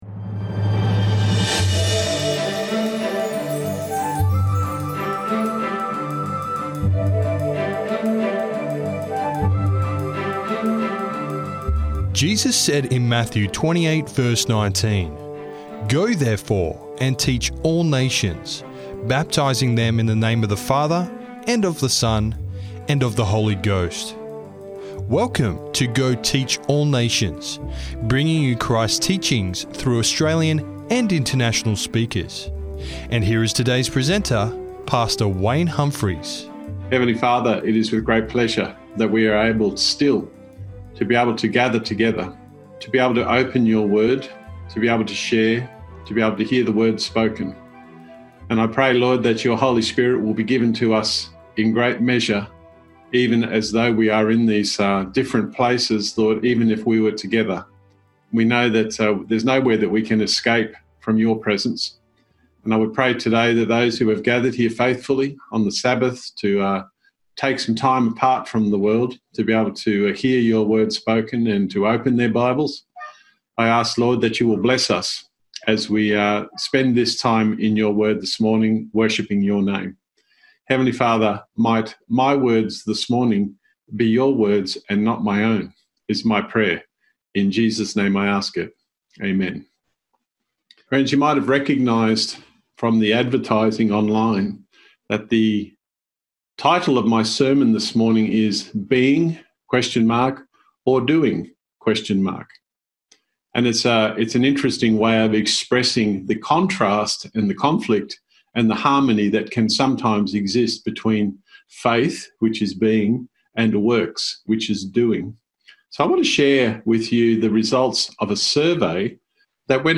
The Harmony of Being and Doing in Christ - Sermon Audio 2604